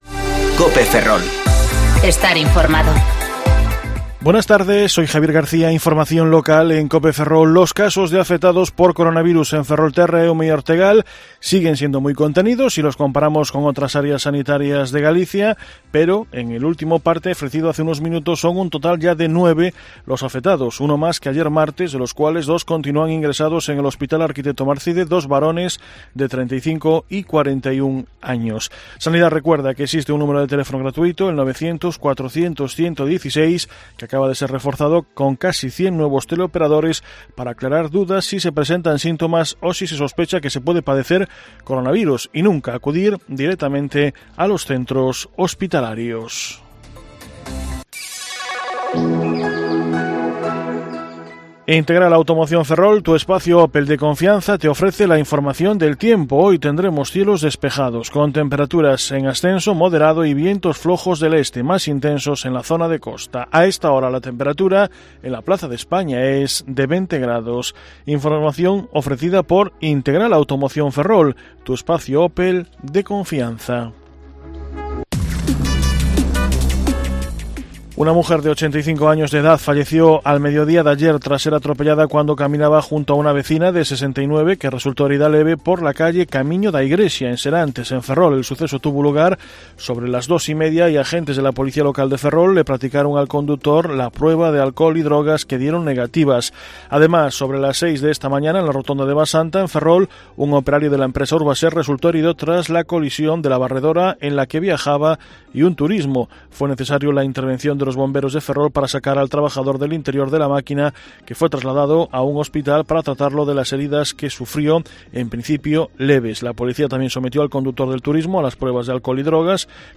Informativo Mediodía COPE Ferrol 18/3/2020